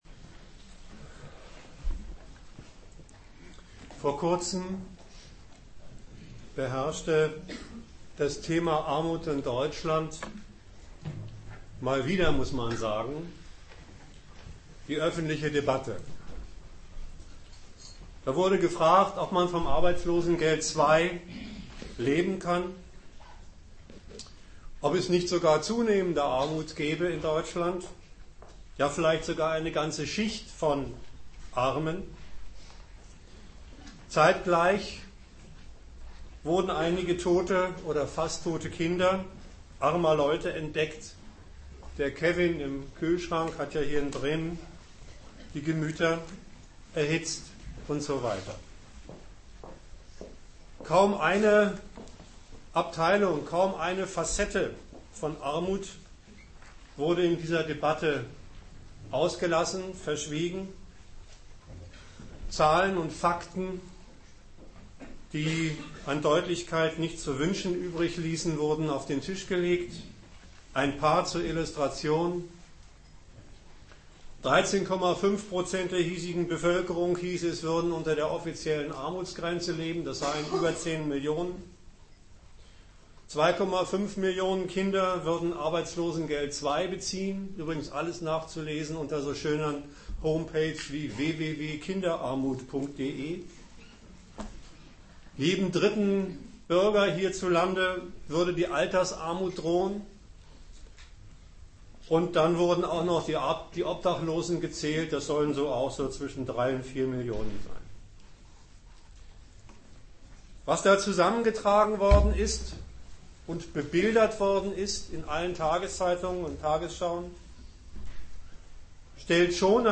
Gliederung des Vortrages:1.